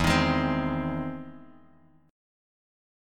E+M7 chord